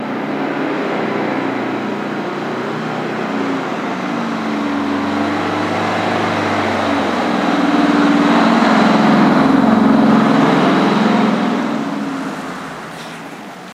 Highwayman Truck By Slower, Shorter